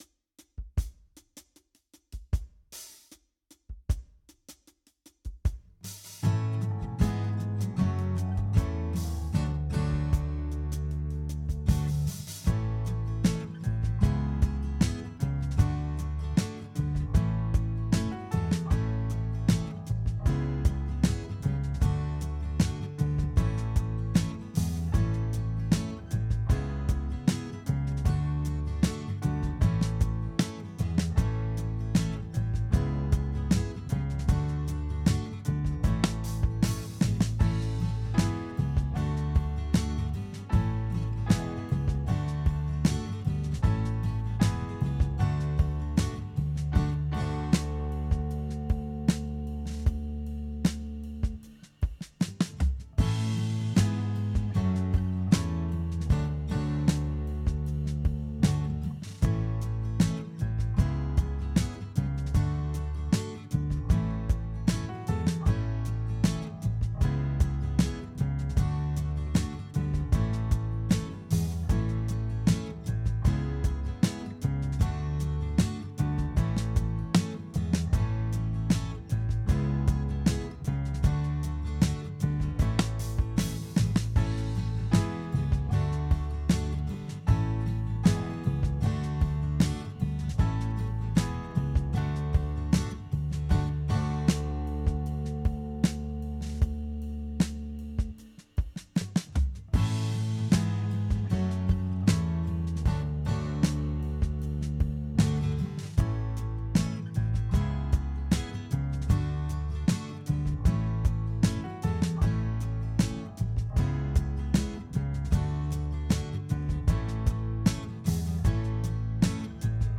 Jam Track
Jam track